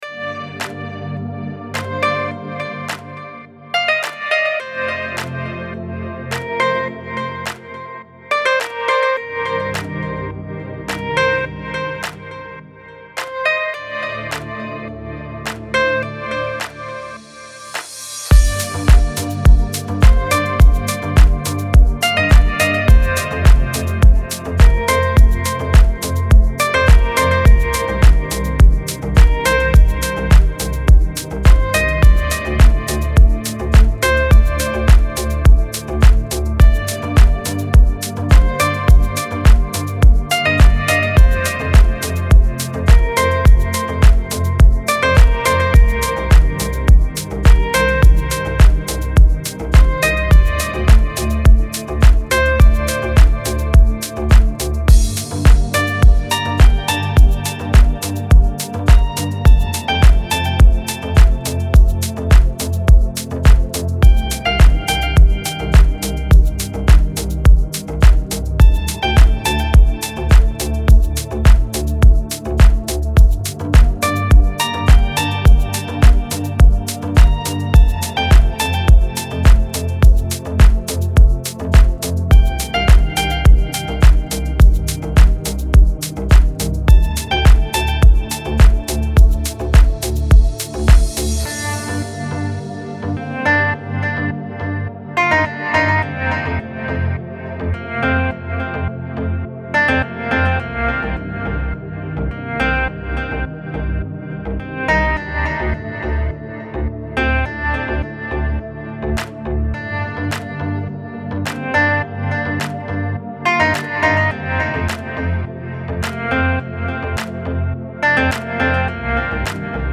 دیپ هاوس
ریتمیک آرام